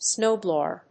アクセント・音節snów・blòwer